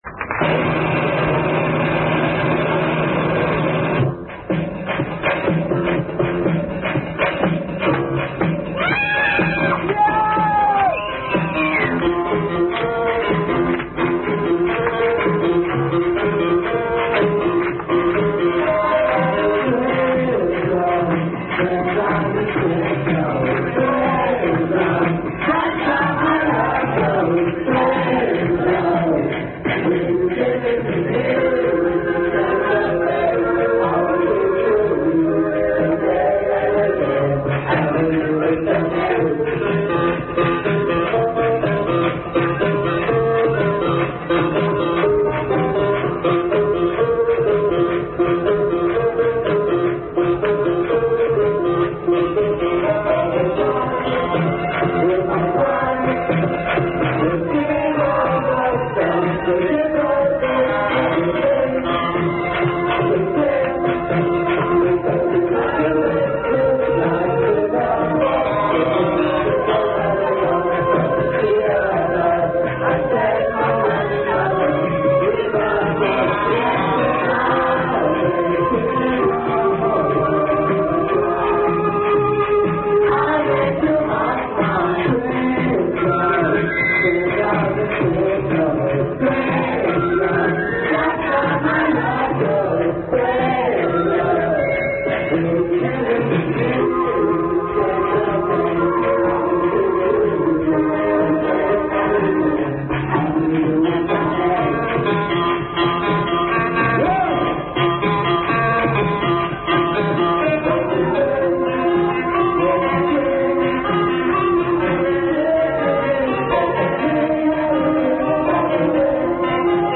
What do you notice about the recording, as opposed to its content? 9.05.91.- France, Dunkerque